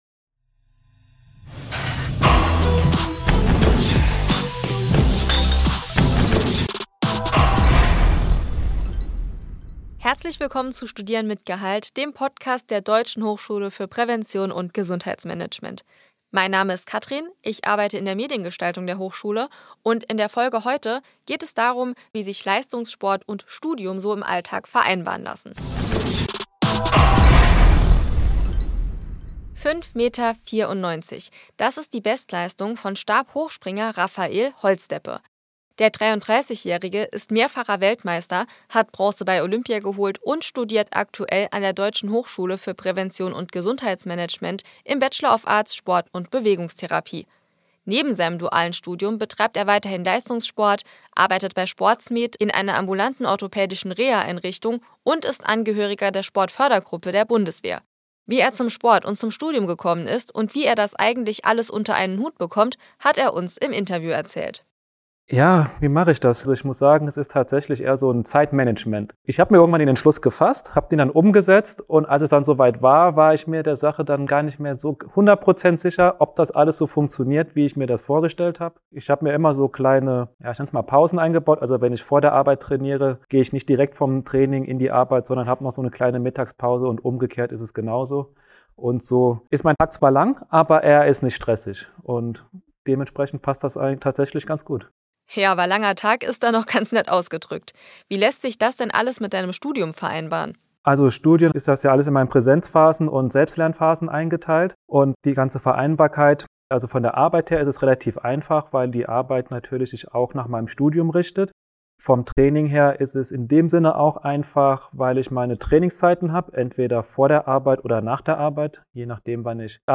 Stabhochspringer Raphael Holzdeppe im Interview über sein Studium und den Leistungssport ~ Studieren mit Gehalt Podcast